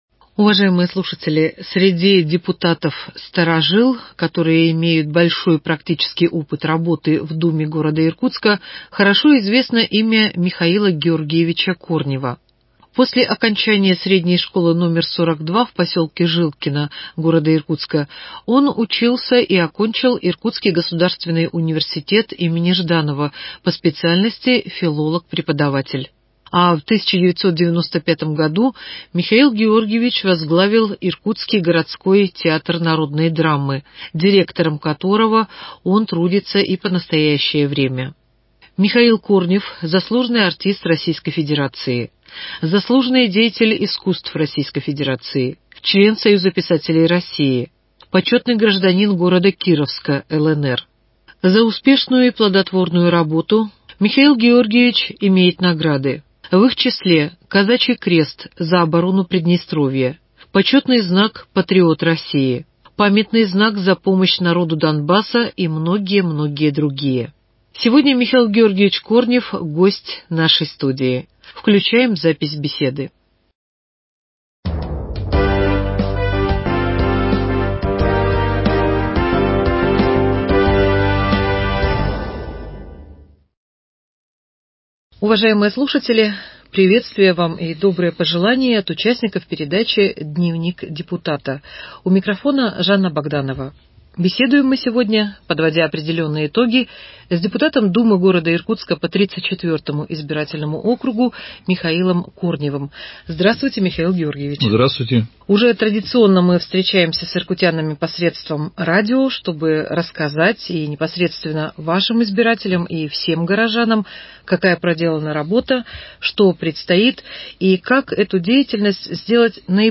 беседует с депутатом Городской Думы по 34 избирательному округу г. Иркутска, директором Иркутского театра народной драмы Михаилом Георгиевичем Корневым.